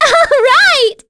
Cleo-Vox_Happy7.wav